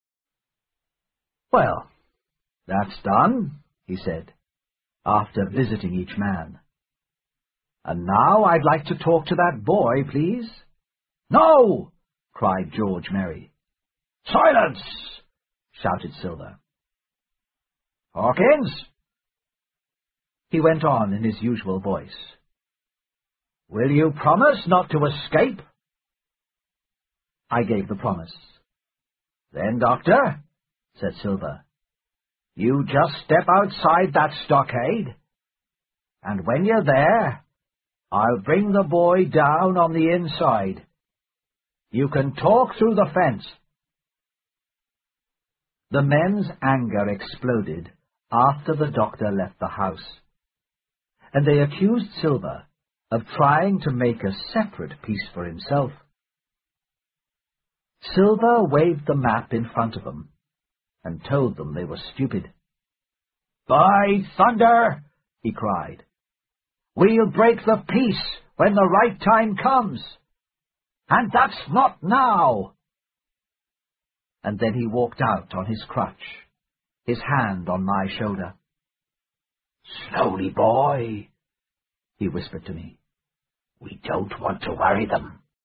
在线英语听力室《金银岛》的听力文件下载,《金银岛》中英双语有声读物附MP3下载